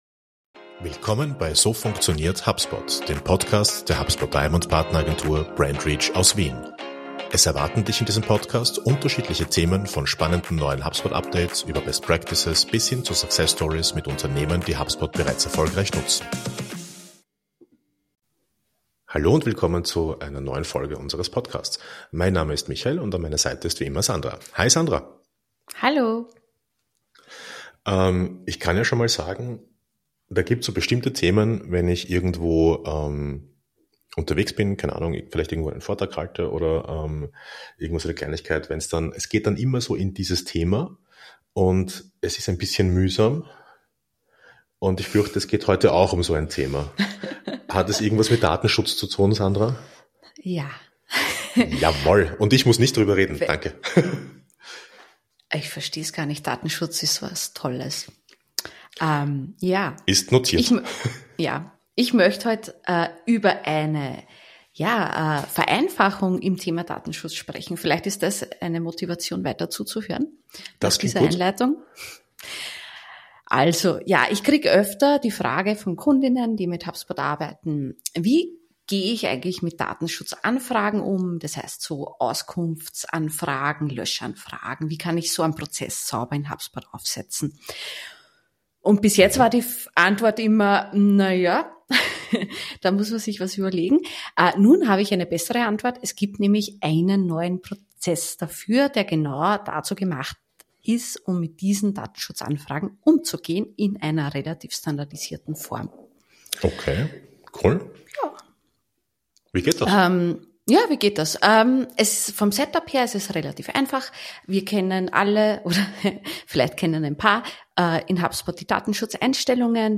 Außerdem gehen die beiden auf praktische Fragen ein, etwa wie man das Formular optimal platziert, wie man manuelle Anfragen nachtragen kann und warum das neue Tool nicht nur für kostenpflichtige, sondern auch für Free-User verfügbar ist. Ein Gespräch, das zeigt: Datenschutz muss nicht kompliziert sein – mit den richtigen Tools wird er zu einer gut handhabbaren Aufgabe.